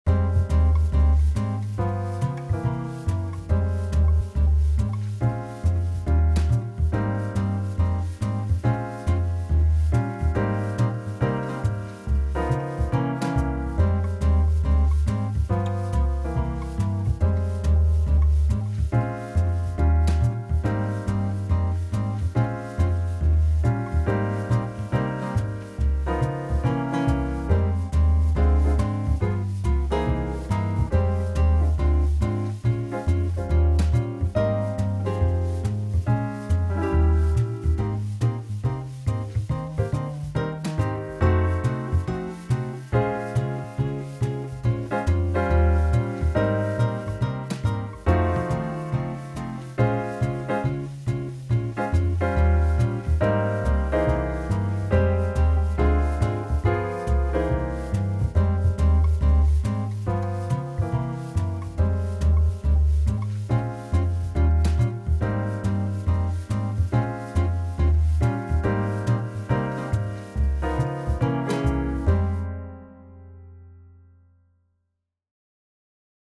Jazz
Driving